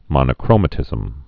(mŏnə-krōmə-tĭzəm)